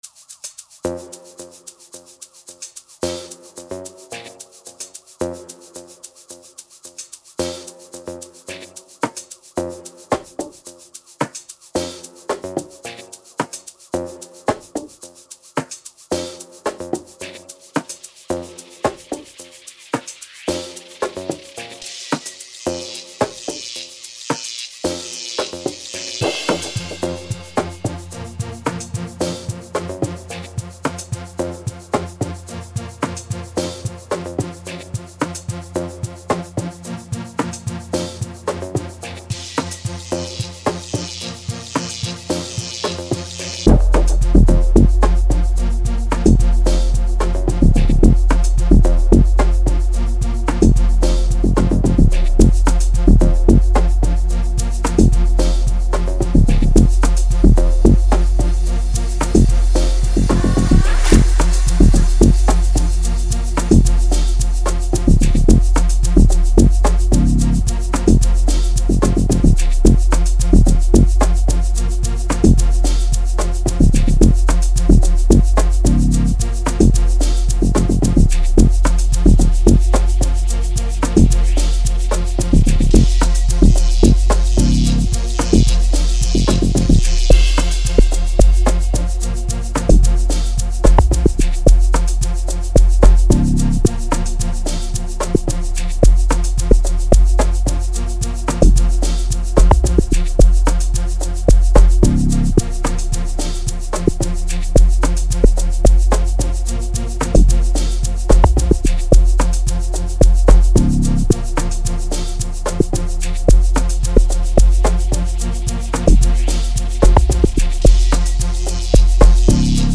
06:15 Genre : Amapiano Size